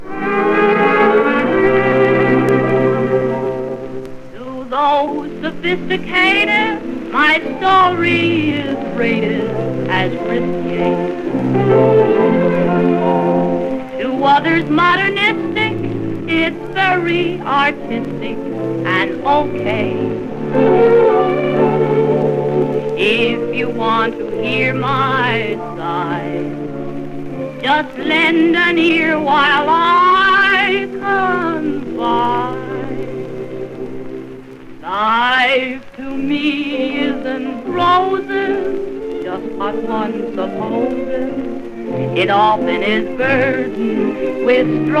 音源は1930年代の物のみです。
Jazz, Pop, Vocal　USA　12inchレコード　33rpm　Mono
ジャケ汚れ　盤良好　元音源に起因するノイズ有